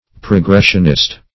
Search Result for " progressionist" : The Collaborative International Dictionary of English v.0.48: Progressionist \Pro*gres"sion*ist\, n. [1913 Webster] 1.
progressionist.mp3